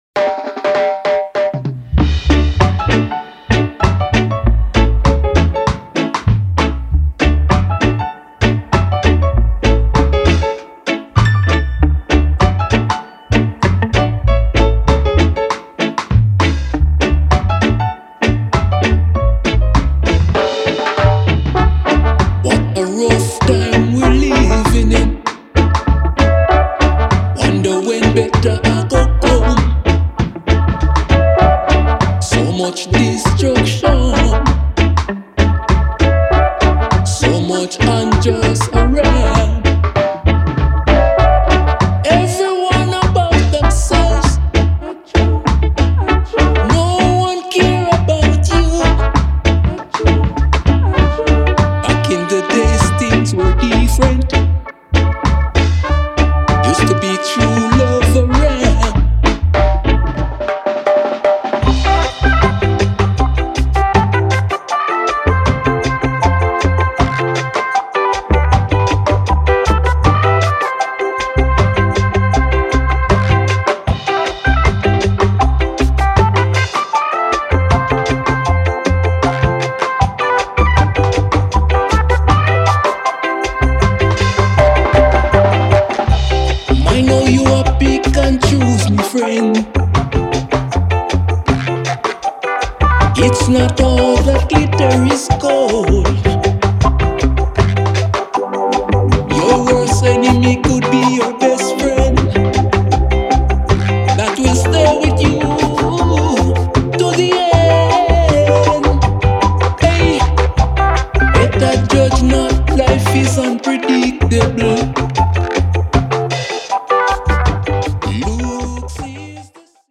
Vocal + Vocal